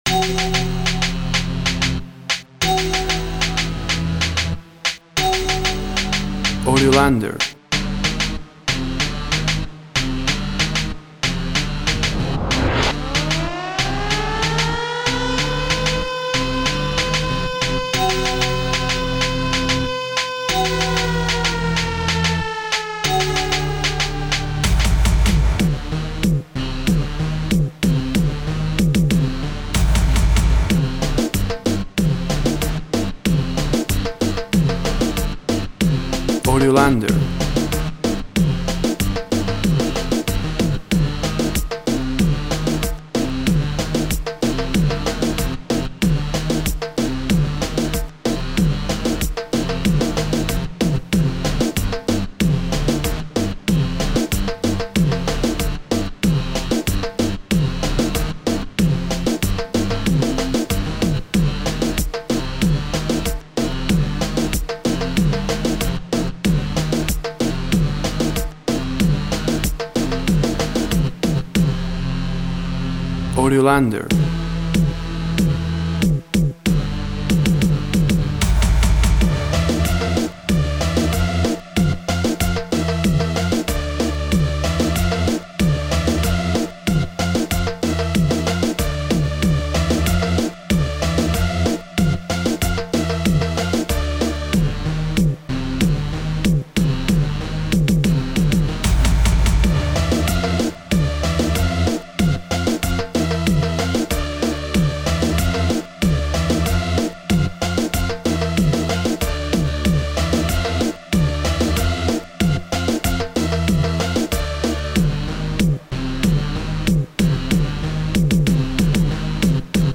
Instrumental Reggaeton.
WAV Sample Rate 24-Bit Stereo, 44.1 kHz
Tempo (BPM) 100